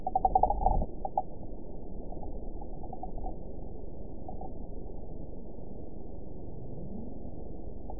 event 912098 date 03/18/22 time 05:46:39 GMT (3 years, 1 month ago) score 6.67 location TSS-AB05 detected by nrw target species NRW annotations +NRW Spectrogram: Frequency (kHz) vs. Time (s) audio not available .wav